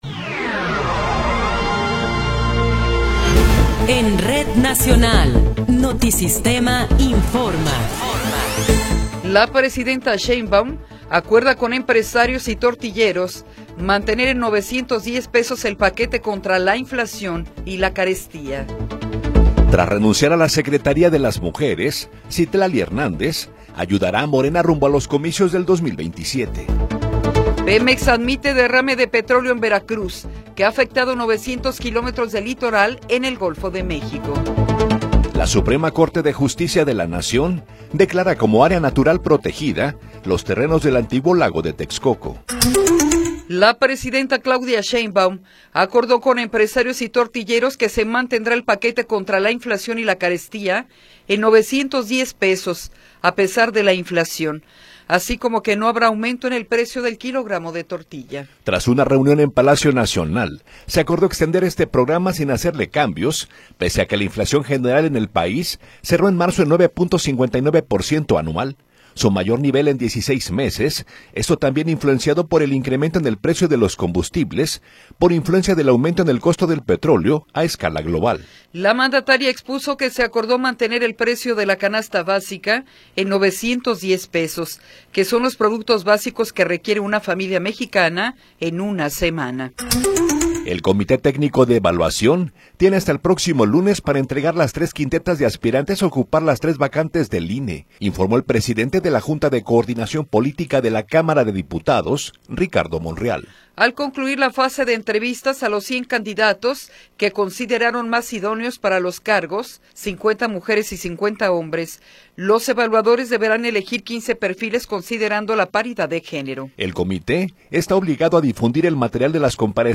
Noticiero 8 hrs. – 17 de Abril de 2026
Resumen informativo Notisistema, la mejor y más completa información cada hora en la hora.